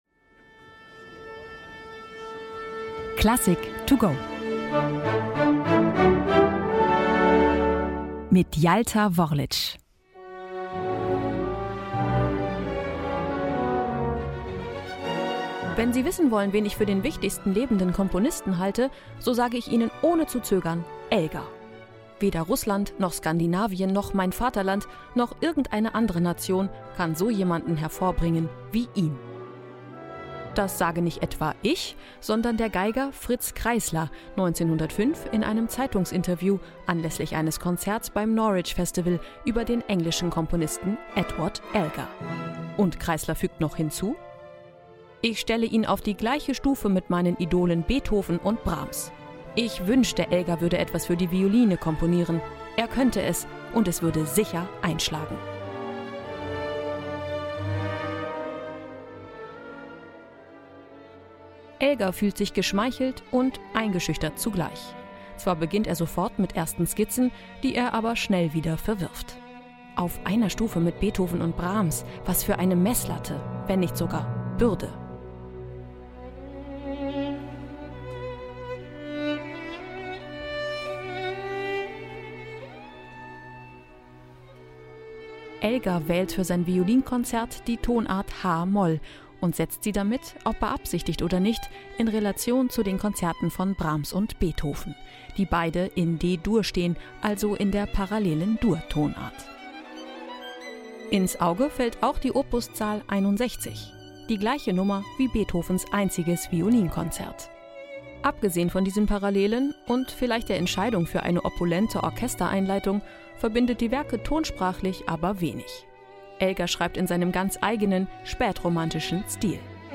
Die kurze Werkeinführung für unterwegs